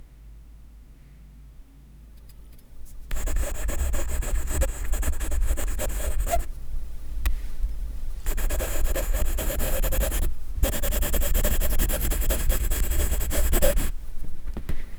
Pencil scratching + exaggerated sigh
pencil-scratching--exagge-6ucjjmog.wav